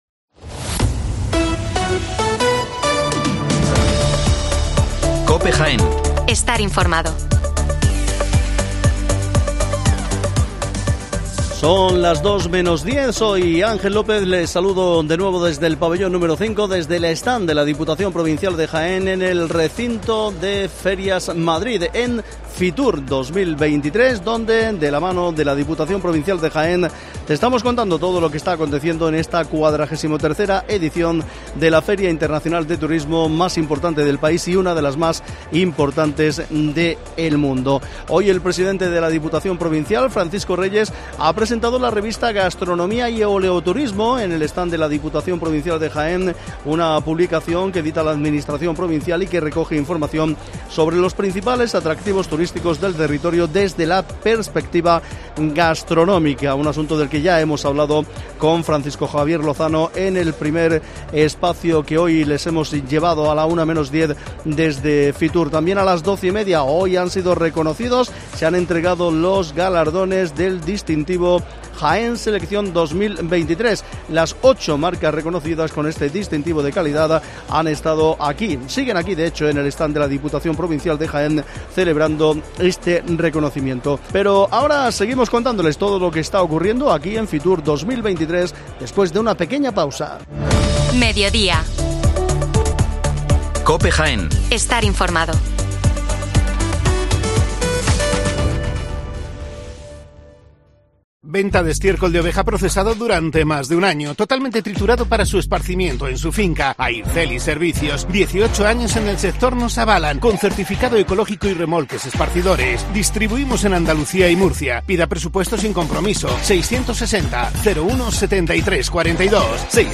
La propuesta turística de Villacarrillo es hoy la protagonista de nuestro "Especial FITUR 2023" en el stand de Diputación.